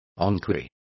Complete with pronunciation of the translation of enquiries.